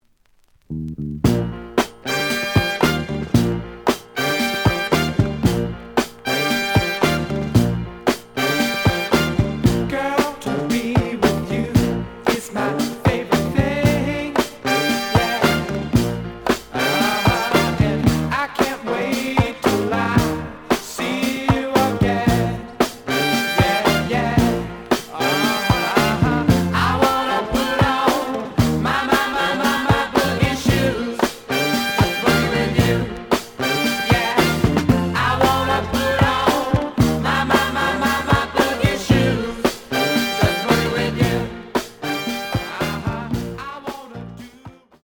The audio sample is recorded from the actual item.
●Genre: Disco
B side plays good.)